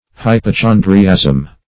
Hypochondriasm \Hy`po*chon"dri*asm\, n.
hypochondriasm.mp3